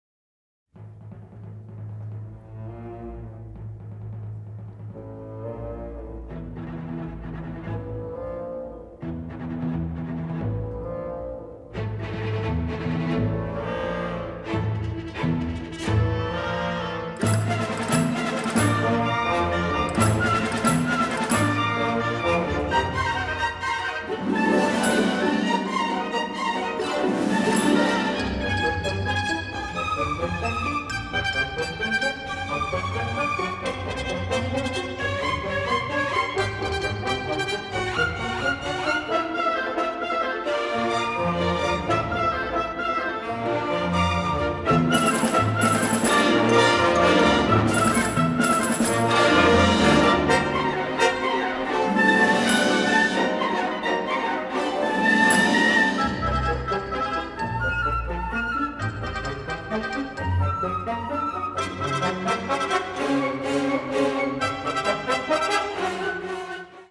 Original Calrec Soundfield - Stereo Microphone Mix